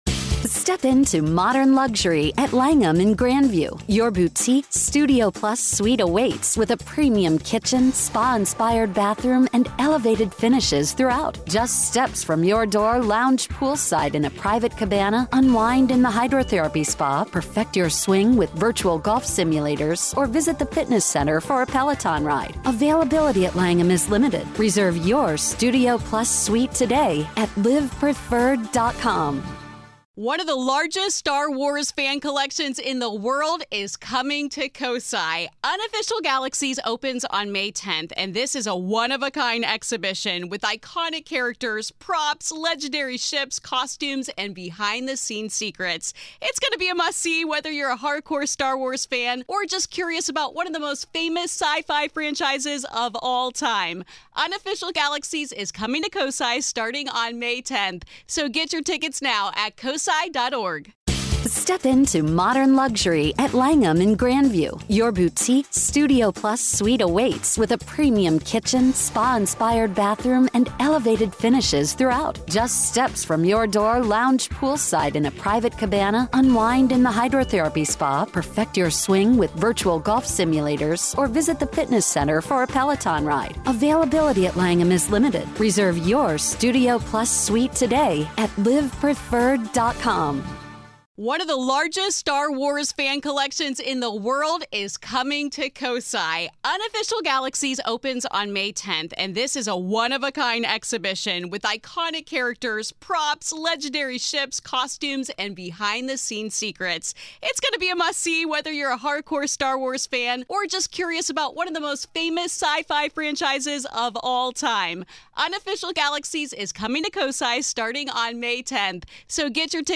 In this conversation, we talk about the eerie and the heartwarming sides of the paranormal—revealing moments of unseen forces, emotional encounters, and a deep connection with the spirit world.